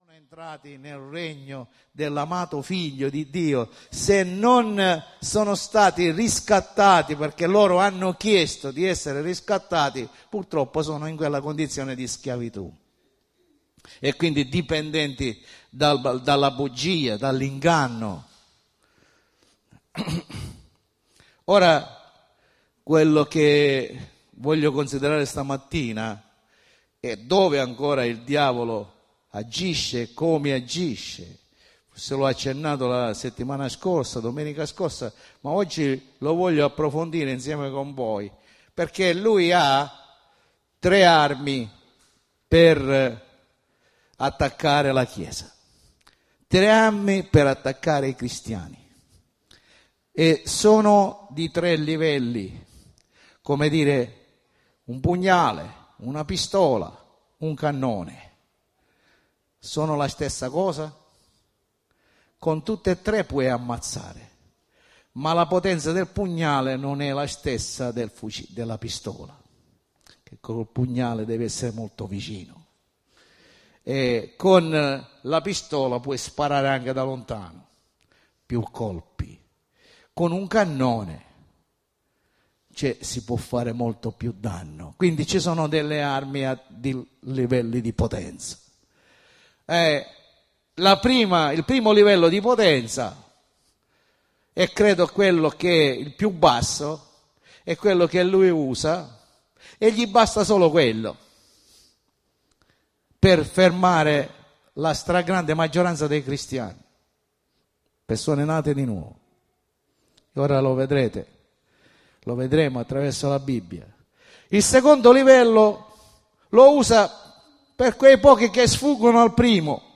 Sezione del sito per l'ascolto dei messaggi predicati la domenica e per il riascolto di studi biblici
Predicazione